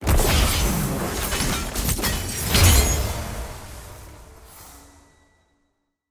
sfx-tier-wings-promotion-to-gold.ogg